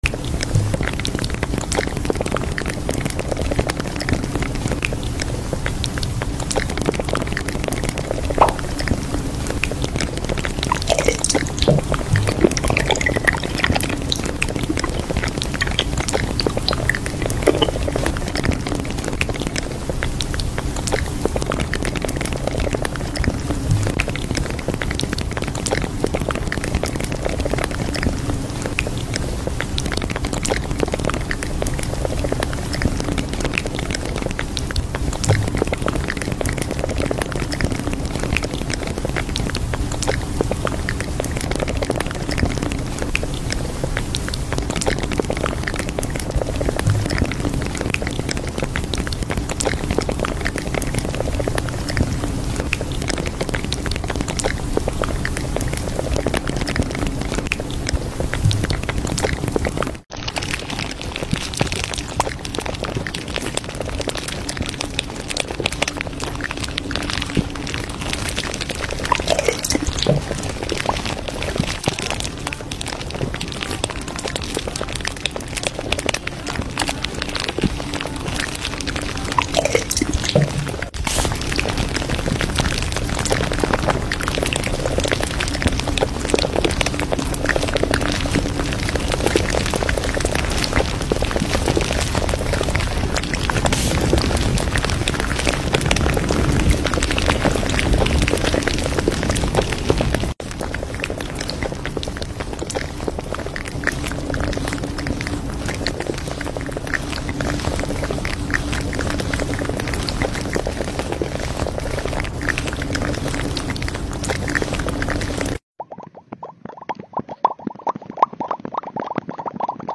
🌴 TW: asmr sounds! sound effects free download